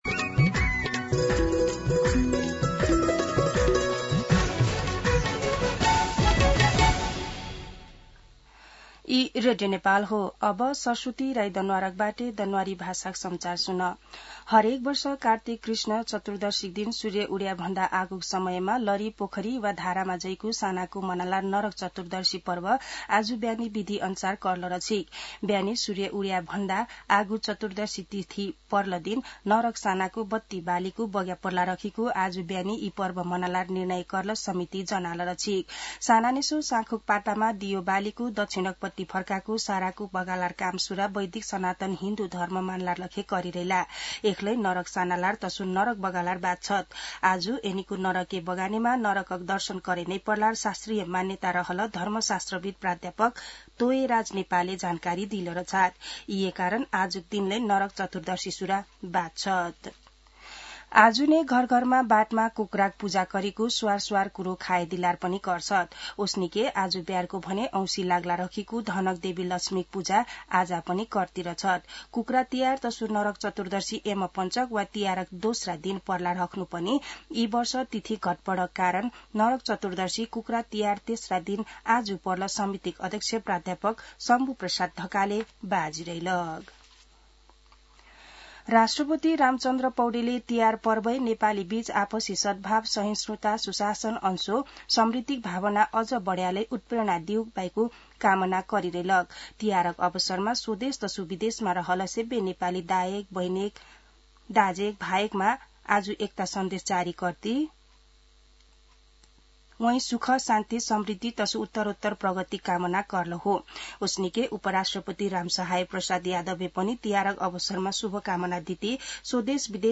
दनुवार भाषामा समाचार : ३ कार्तिक , २०८२